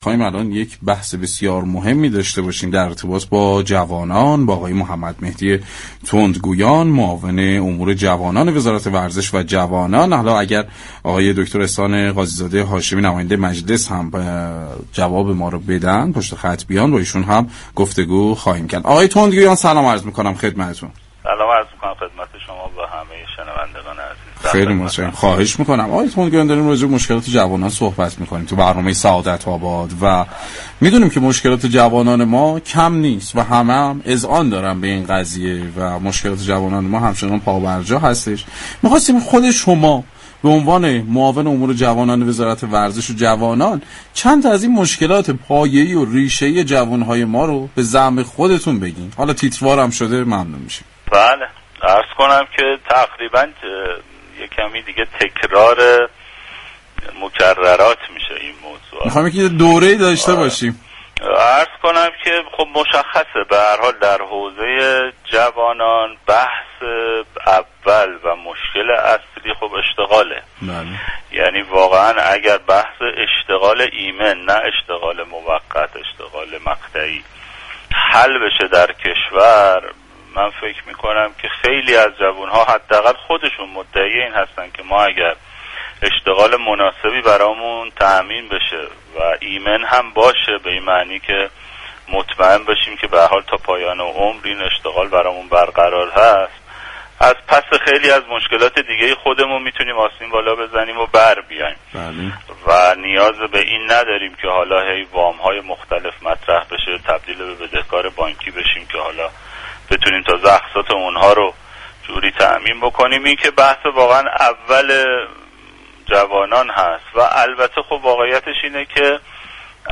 به گزارش پایگاه اطلاع رسانی رادیو تهران، محمد مهدی تندگویان معاون امور جوانان وزارت ورزش و جوانان در برنامه سعادت آباد رادیو تهران گفت: مهمترین مشكلات جوانان اشتغال ایمن است اگر جوانان امنیت شغلی داشته باشند از پس باقی مشكلات برمی‌آیند .البته اگر مشكل اشتغال جوانان هم حل بشود با این مبالغ بالای مسكن كه وجود دارد نیاز به طرح حمایتی مشخص برای مسكن داریم.